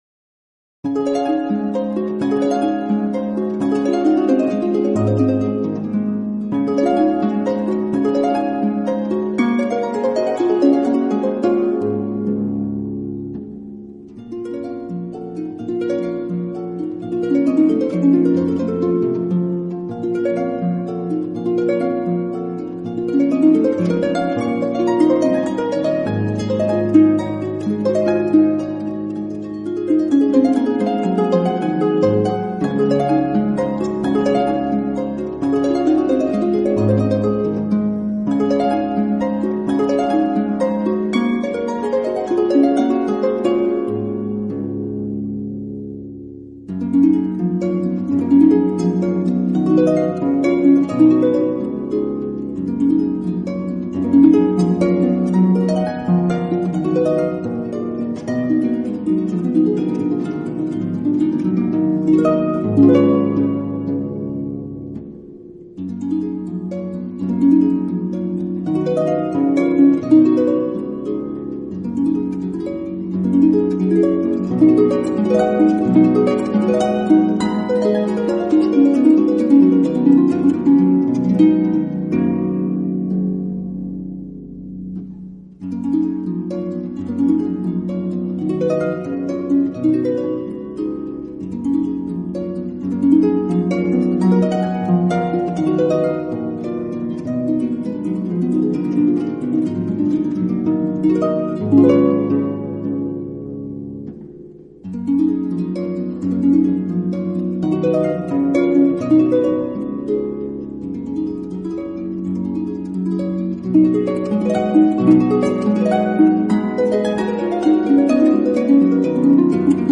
室内乐
for Harp Solo